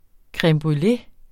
Udtale [ kʁεmbʁyˈle ]